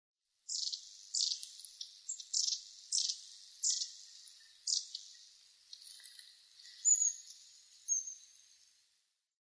エナガ　Aegithalos caudatusエナガ科
日光市稲荷川中流　alt=850m  HiFi --------------
Rec.: SONY MZ-NH1
Mic.: Sound Professionals SP-TFB-2  Binaural Souce